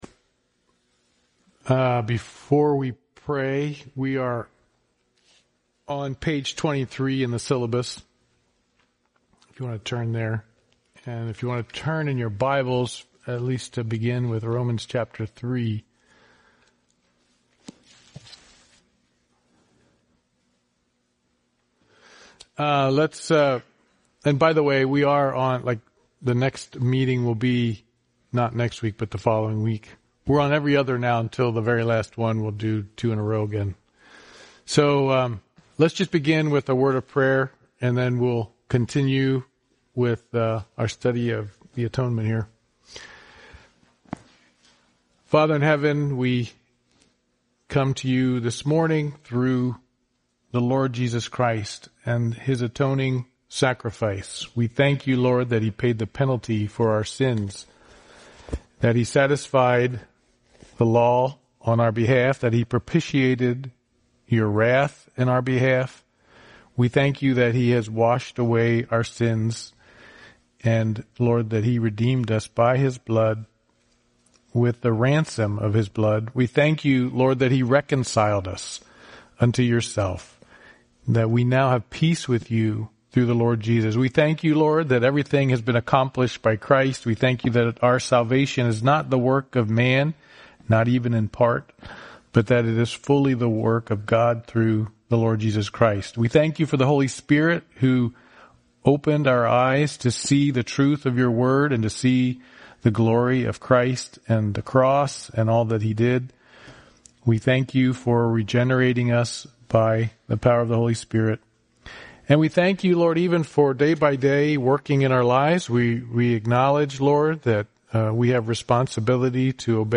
Service Type: Men's Bible Study